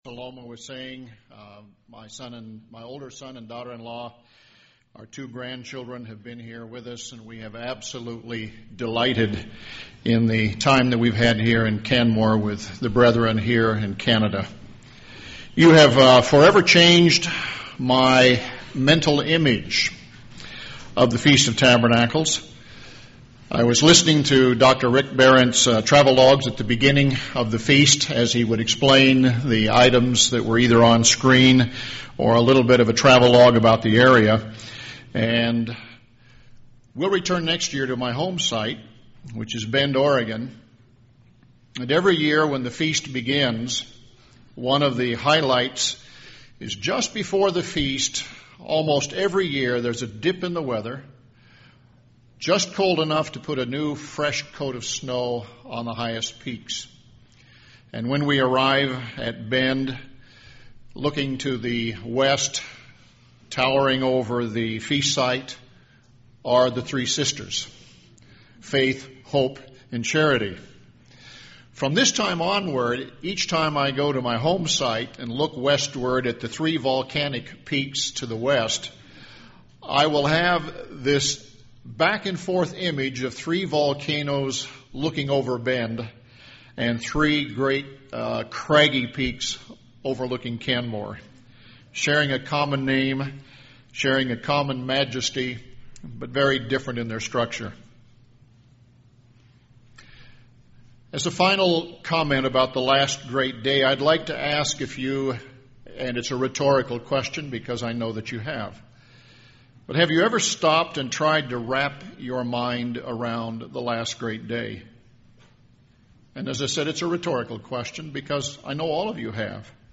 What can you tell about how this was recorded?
This sermon was given at the Canmore, Alberta 2012 Feast site.